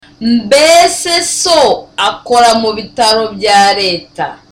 Dialogue: A conversation between Tereza and Sonita